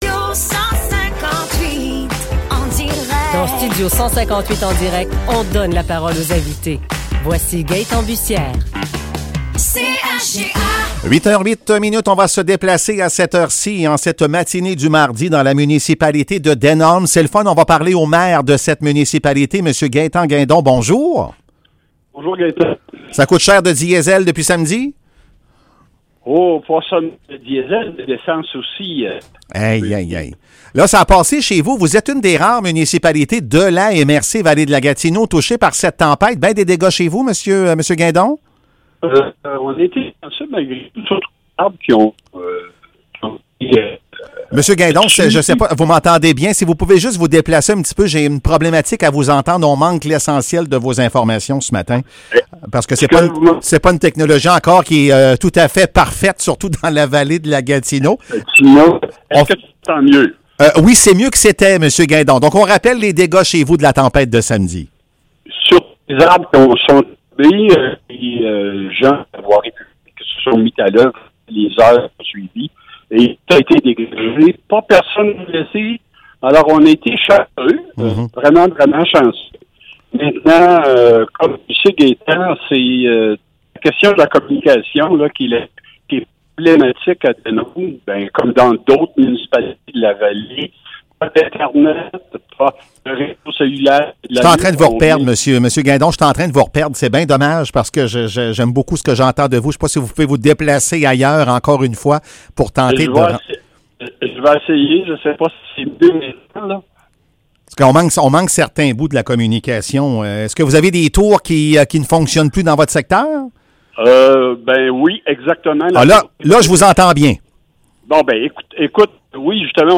Entrevue avec Gaétan Guindon, maire de Denholm